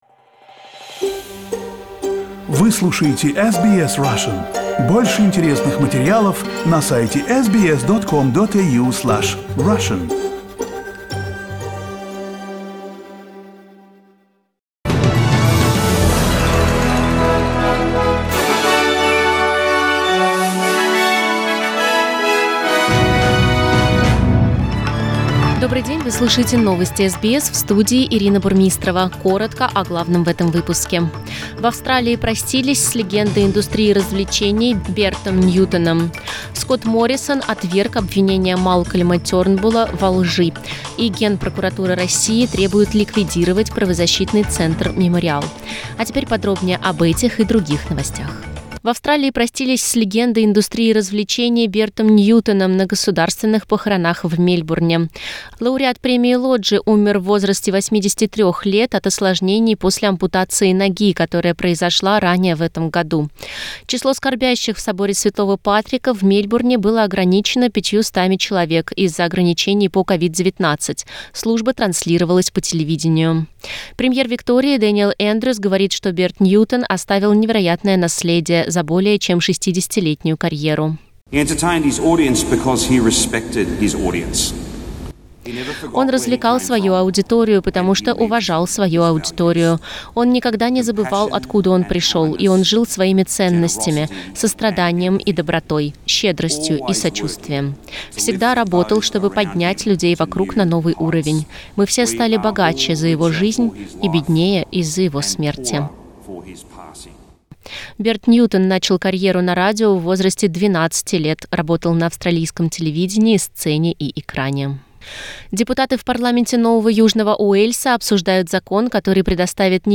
Новости SBS на русском языке - 12.11